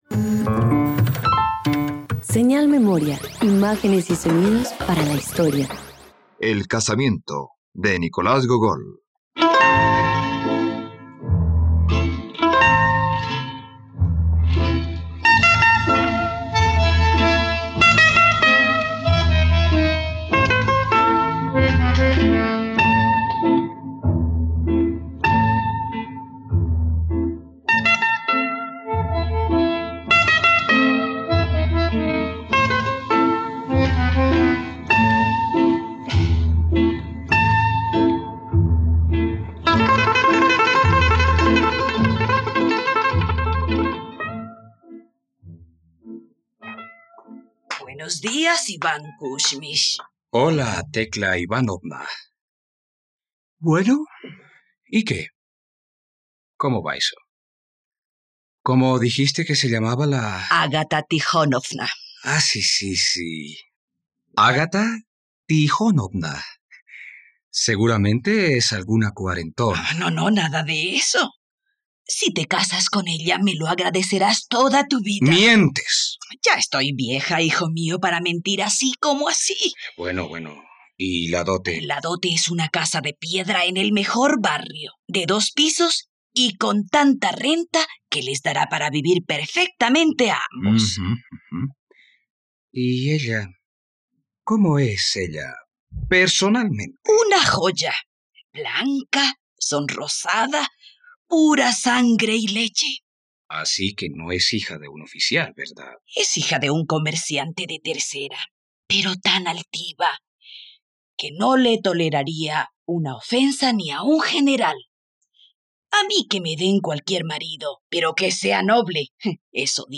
El Casamiento - Radioteatro dominical | RTVCPlay